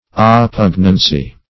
Search Result for " oppugnancy" : The Collaborative International Dictionary of English v.0.48: Oppugnancy \Op*pug"nan*cy\, n. [See Oppugnant .]